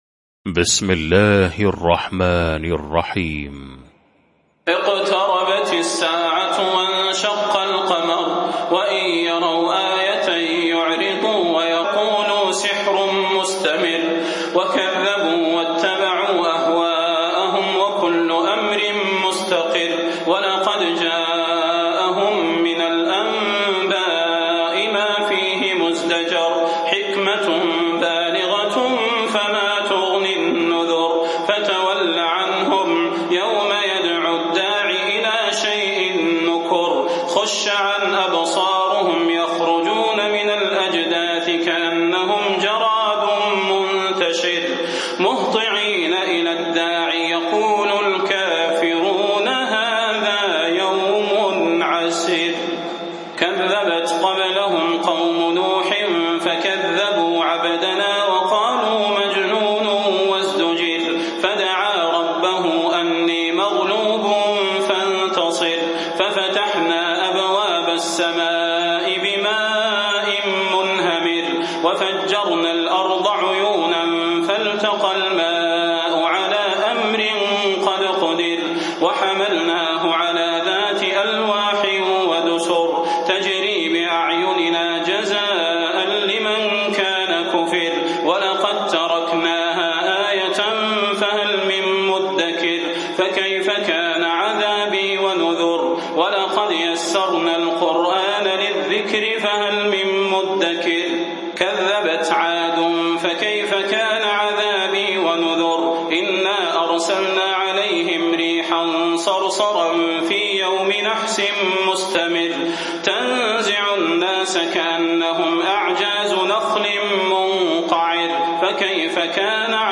المكان: المسجد النبوي الشيخ: فضيلة الشيخ د. صلاح بن محمد البدير فضيلة الشيخ د. صلاح بن محمد البدير القمر